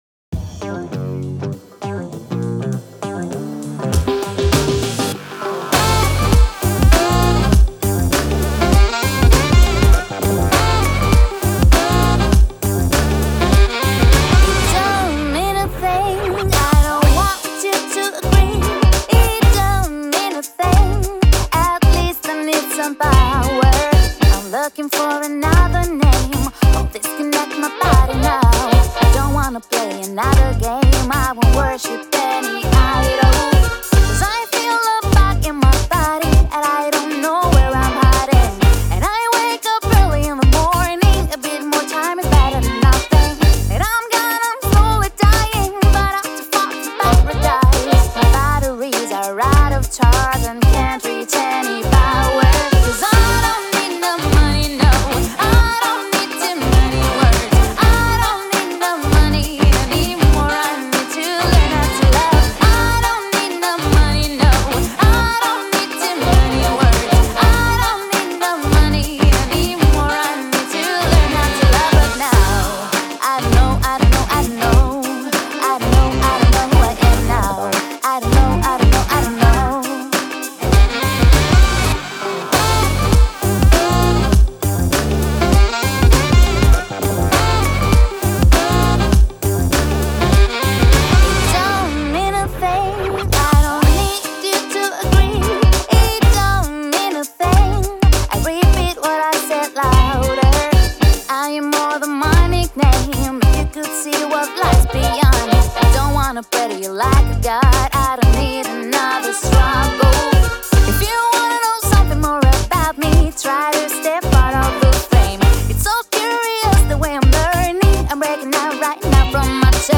BPM100-100
Audio QualityPerfect (High Quality)
Neo swing song for StepMania, ITGmania, Project Outfox
Full Length Song (not arcade length cut)